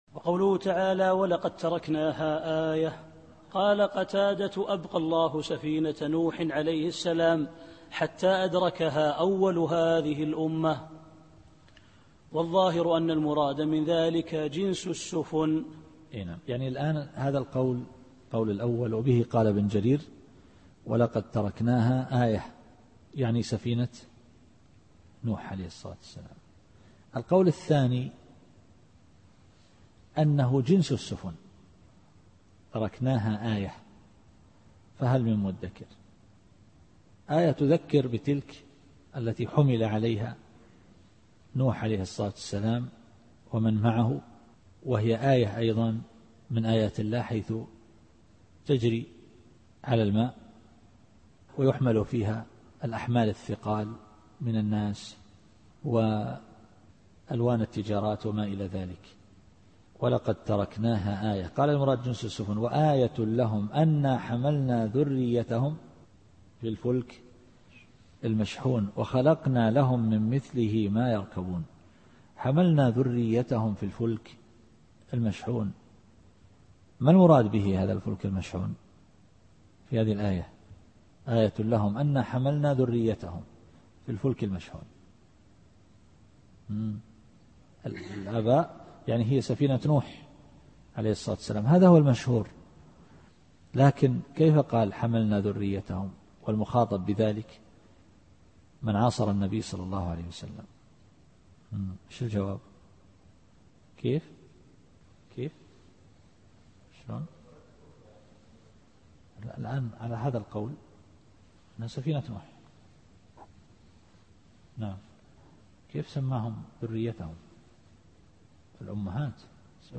التفسير الصوتي [القمر / 15]